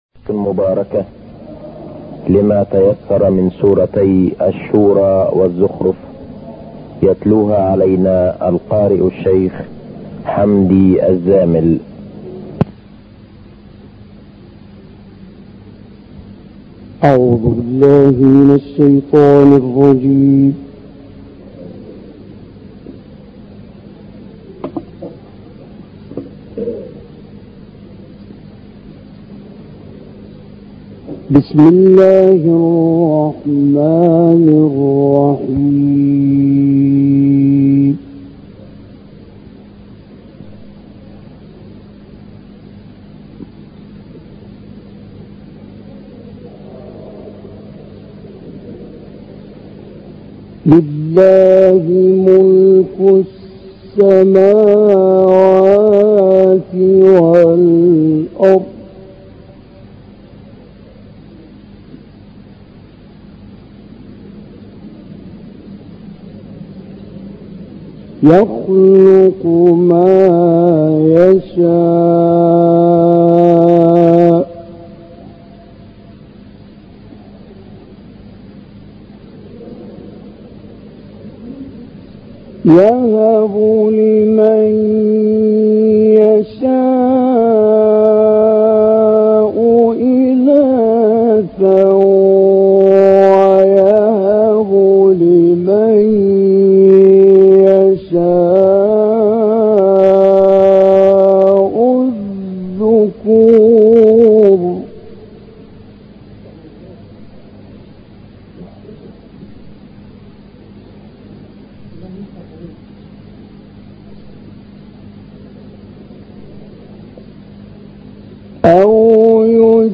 در مسجد امام حسین(ع) مصر